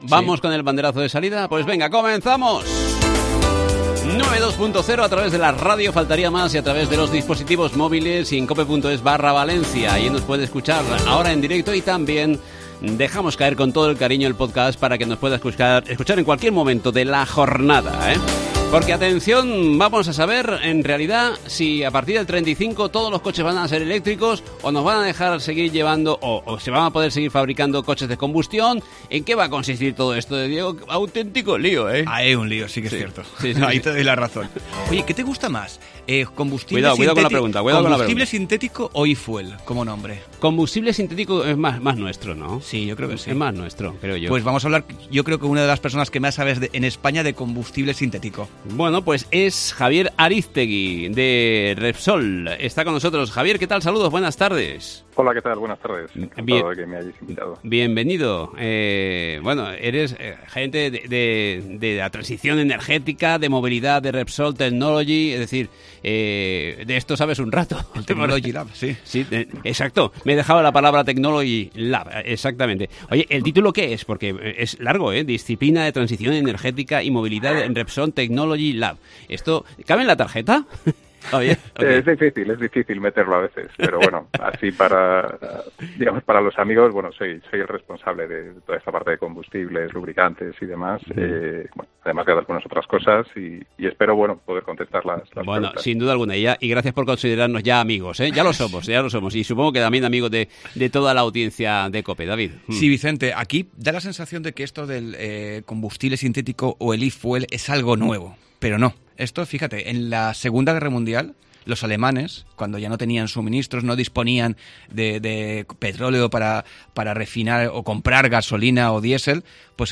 Luz de cruce Entrevista